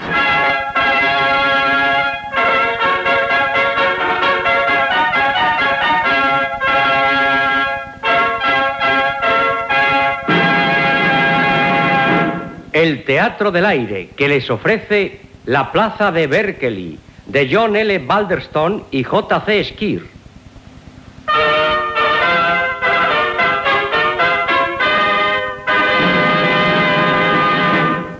Careta del programa que adaptava a la ràdio "La plaza de Berkeley" de John L. Balderston i John Collins Square.
Ficció